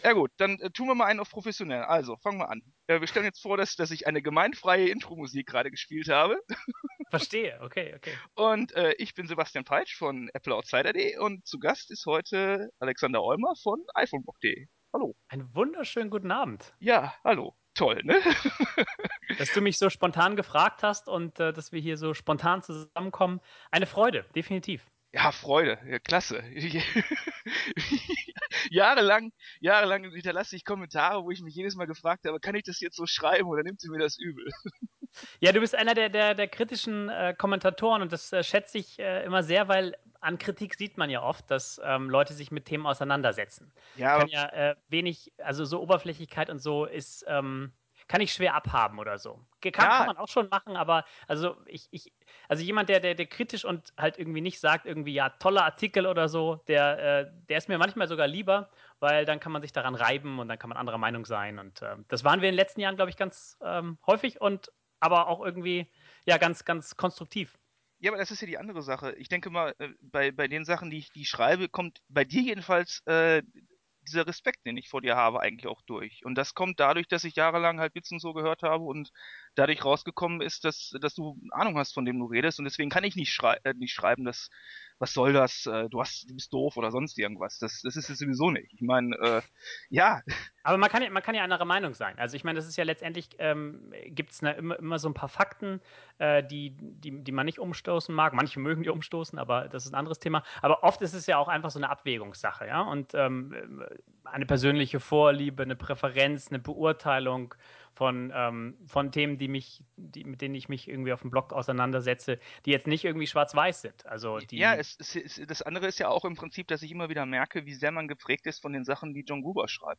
Tja so ist das – man fragt freundlich und dann kommen knackige 1:28 Stunden Podcast bei rum mit einem Gespräch, das netter nicht hätte sein können.